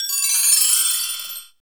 Index of /90_sSampleCDs/Roland L-CD701/PRC_Asian 2/PRC_Windchimes
PRC BELL 0DL.wav